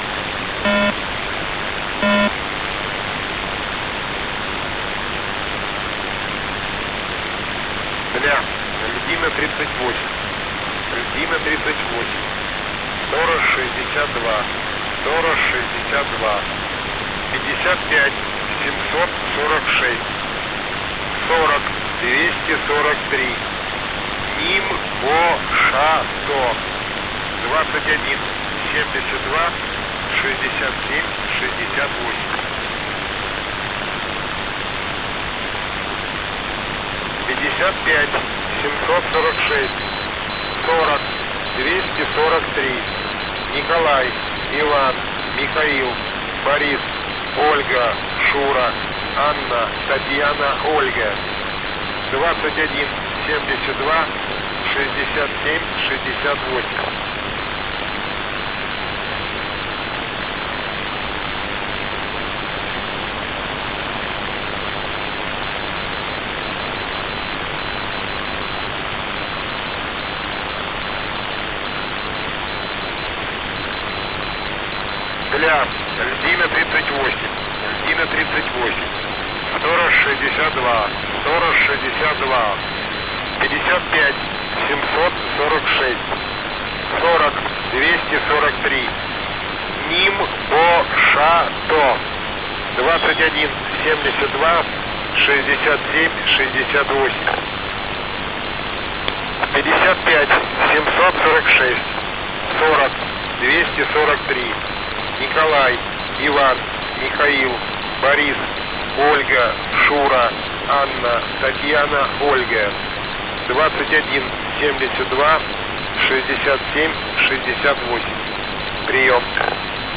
H3E (USB)